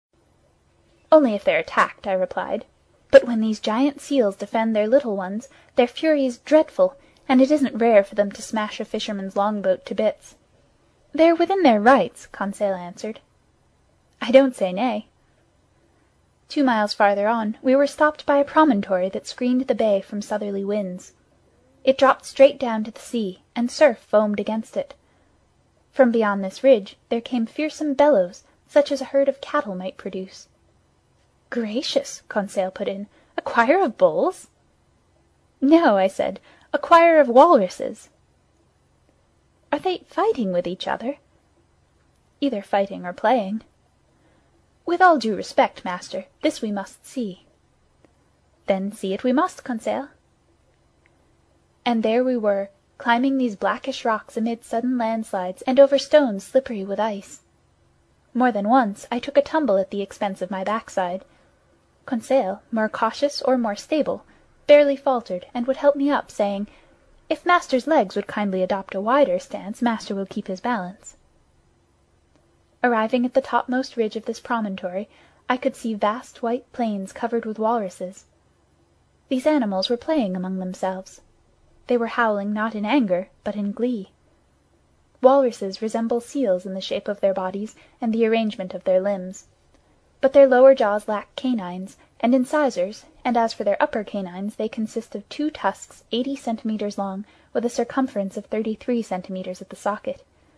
在线英语听力室英语听书《海底两万里》第451期第27章 南极(10)的听力文件下载,《海底两万里》中英双语有声读物附MP3下载